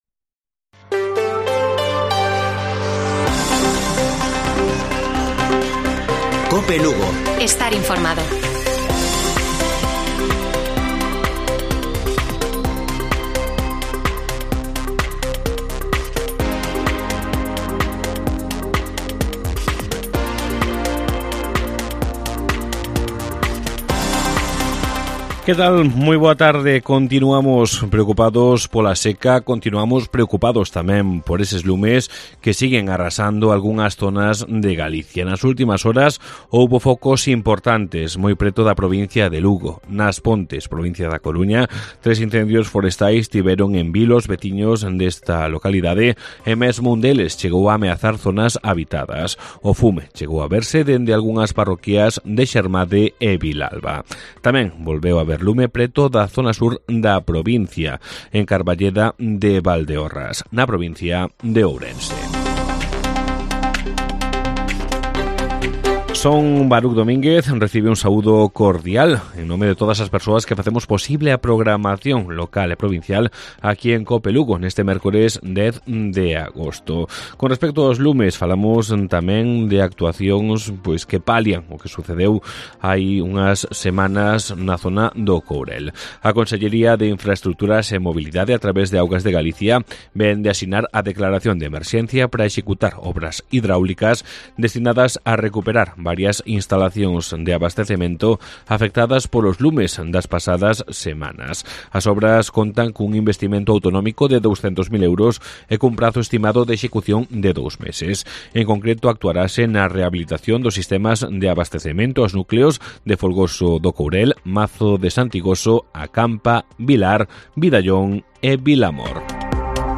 Informativo Mediodía de Cope Lugo. 10 DE AGOSTO. 14:20 horas